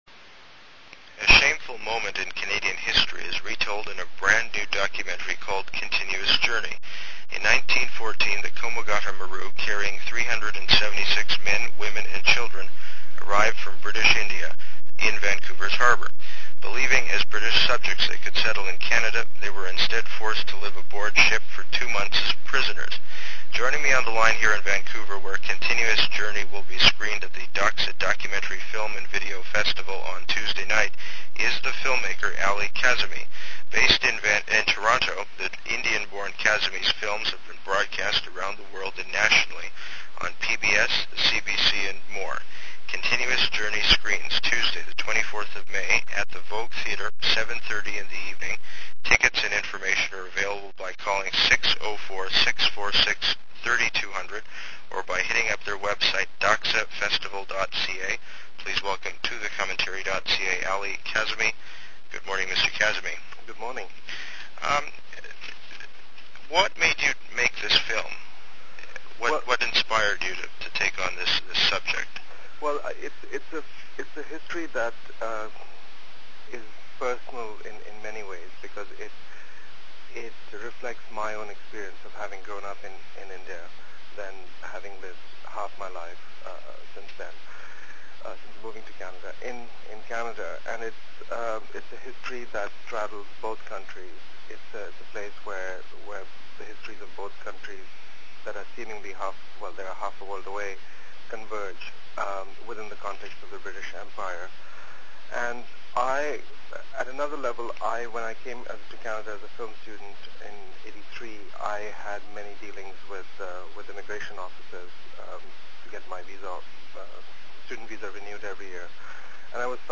It’s a fascinating moment in Canadian history, and a fascinating conversation that’s split into two segments.